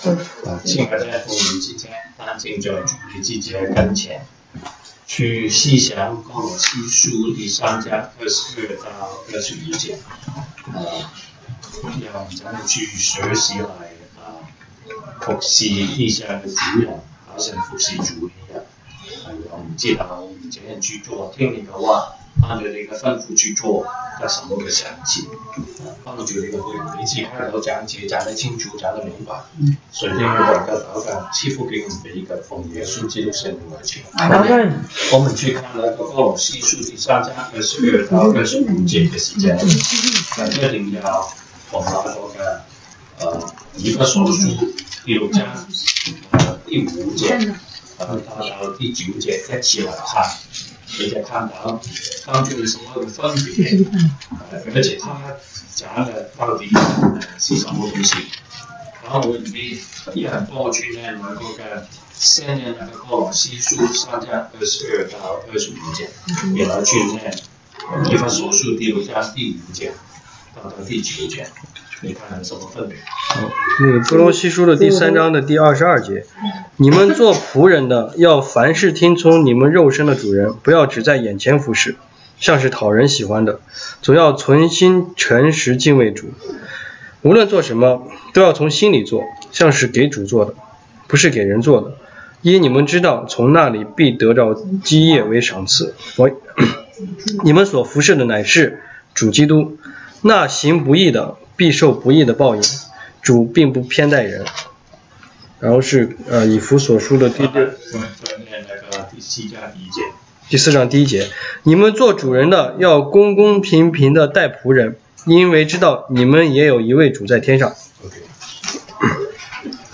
歌羅西書 Colossians 3:22-4:1 Service Type: 週一國語研經 Monday Bible Study « 週一國語研經